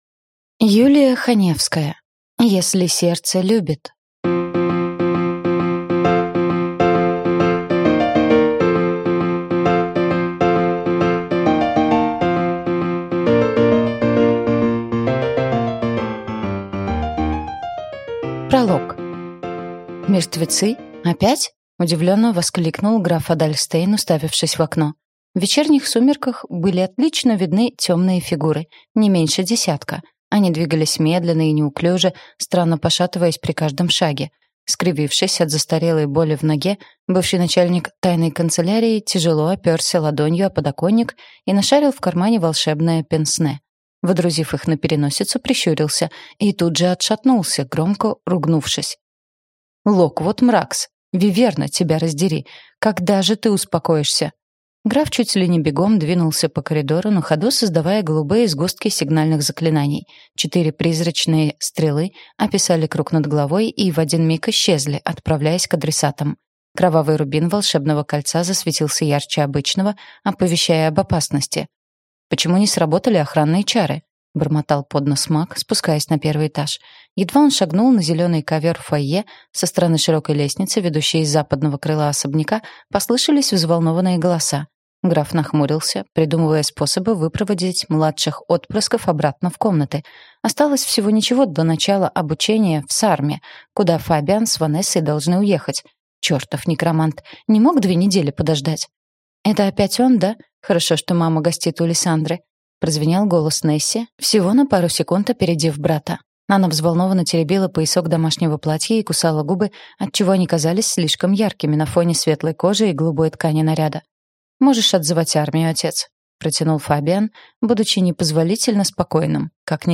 Аудиокнига Если сердце любит | Библиотека аудиокниг